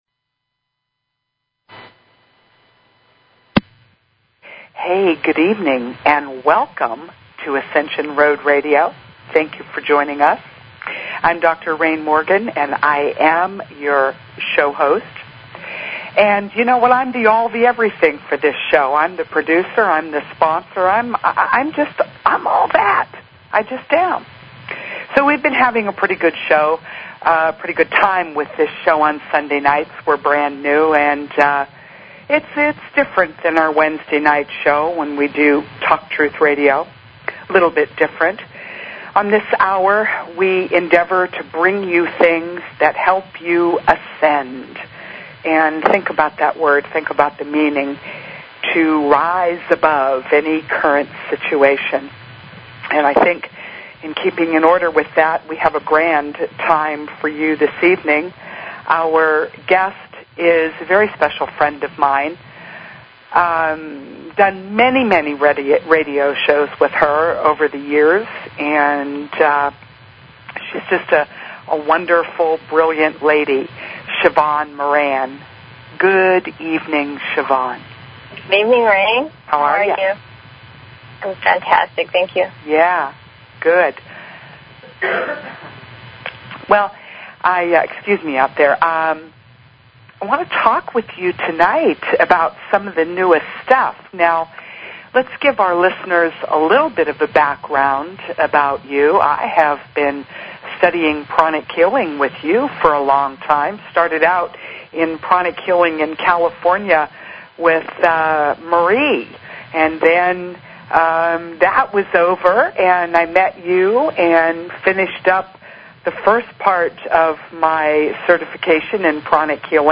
Talk Show Episode, Audio Podcast, Ascension_Road and Courtesy of BBS Radio on , show guests , about , categorized as